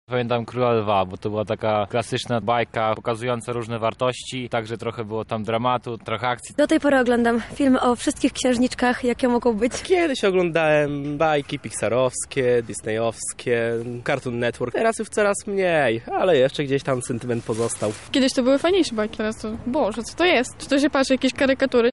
Zapytaliśmy mieszkańców Lublina co sądzą o takich produkcjach, czego ich nauczyły i jak często do nich wracają.